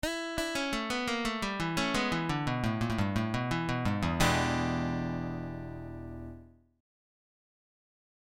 Blues lick > Lick 6